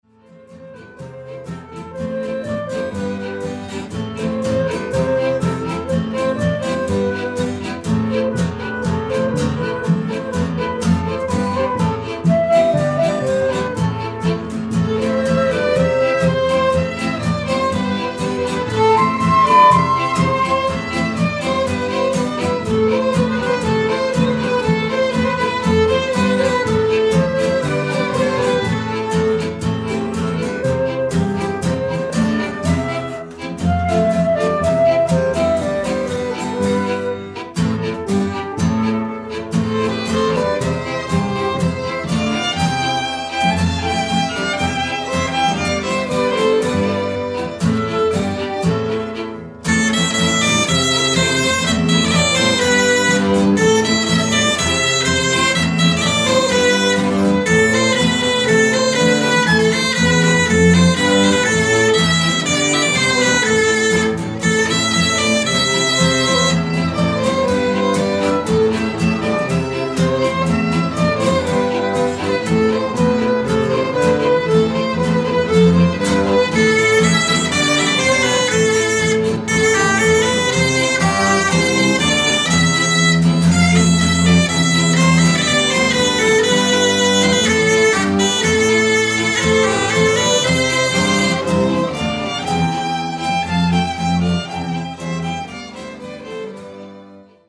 Tanz: Branle